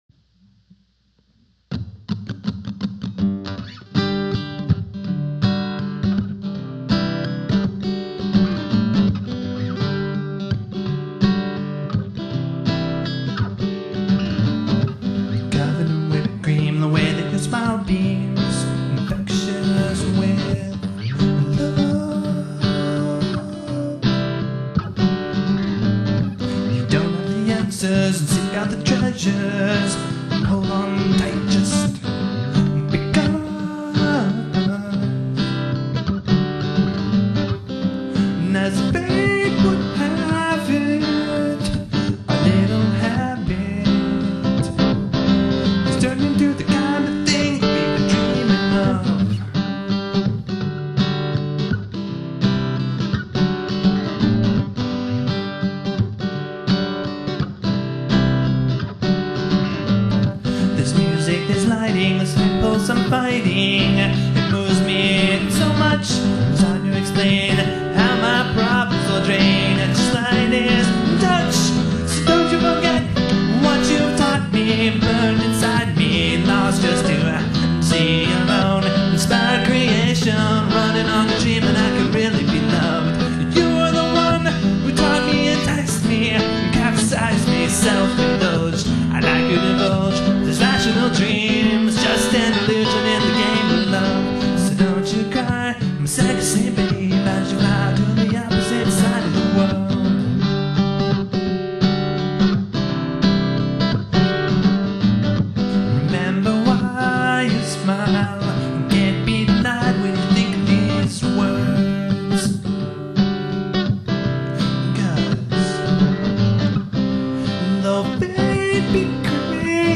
All songs recorded in May 2002 in Dallas, Texas